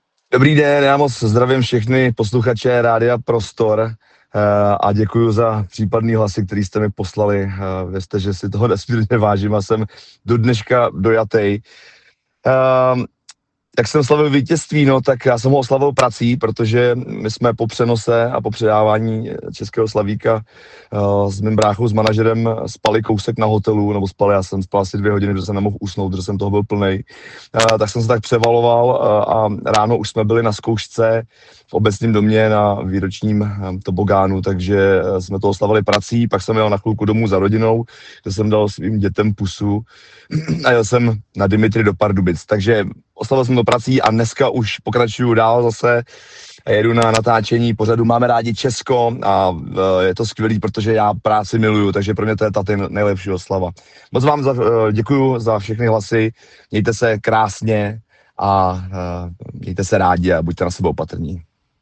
Vzkaz slavíka Václava Noida Bárty